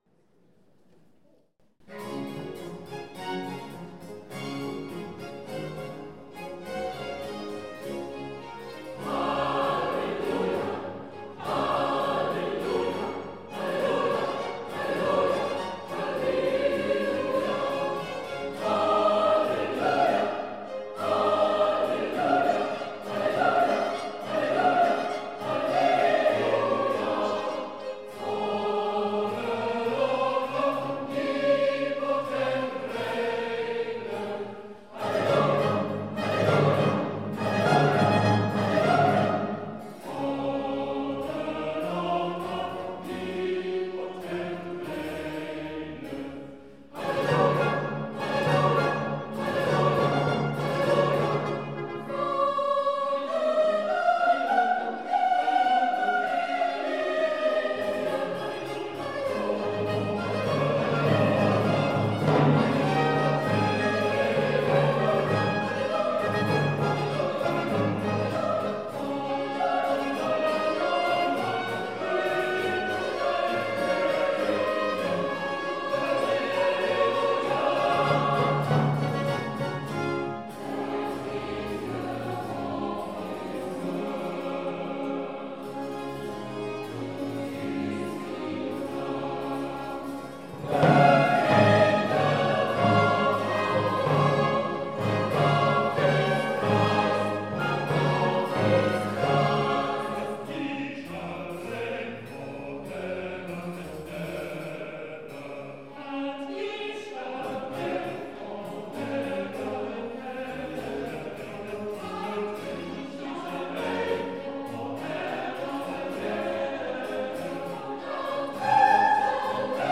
Dubliner Fassung – Oratorium in englischer Sprache in historischer Aufführungspraxis
Konzert des Kammerchores am Sonntag, 09. Juni 2024 um 17:00 Uhr, Stadtkirche Wermelskirchen
…und so klang das HALLELUJAH am 09. Juni 2024 in der Stadtkirche Wermelskirchen (Live Mitschnitt):